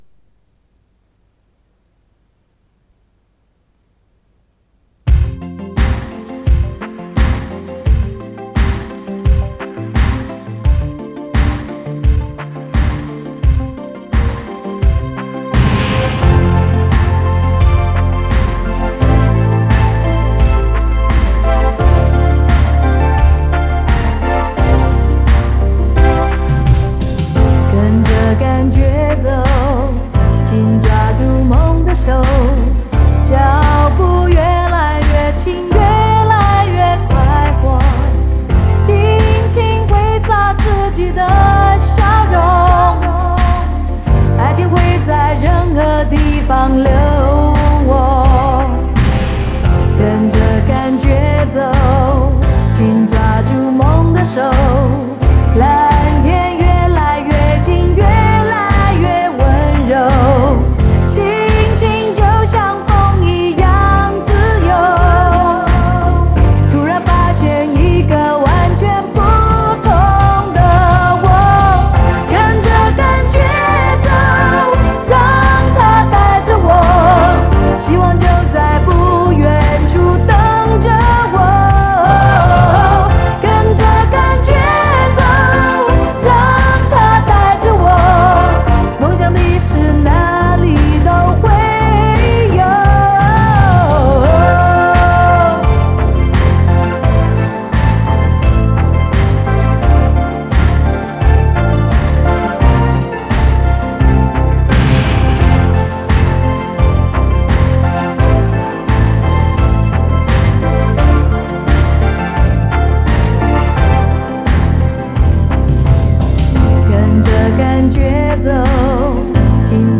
磁带数字化：2022-10-03